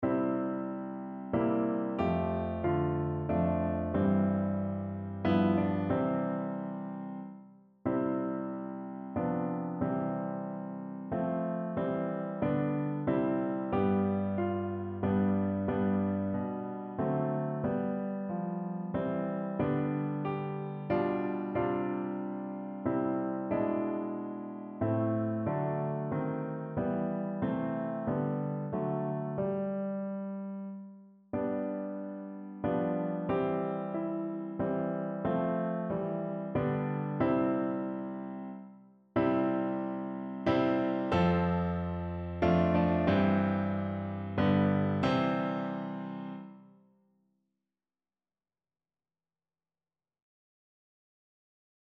Notensatz 3 (4 Männer)